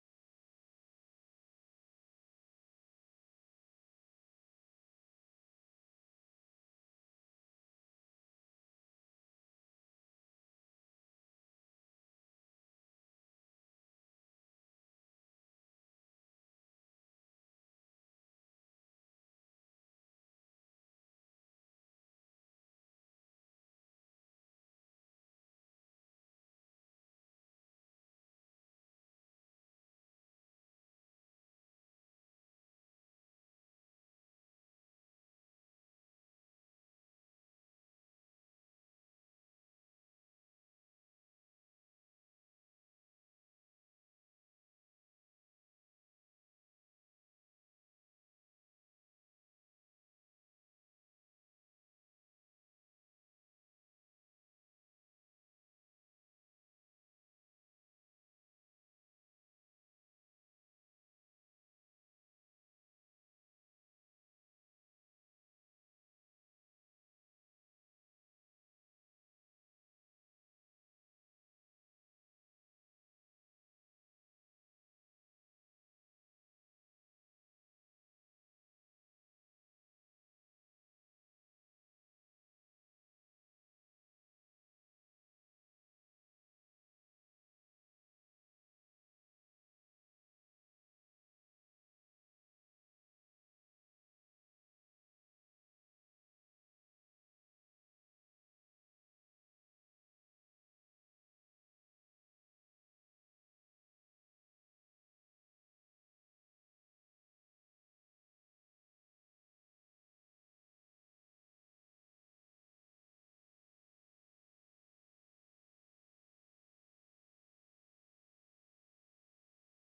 The final praise and worship of 2024 at FWC
Praise Worship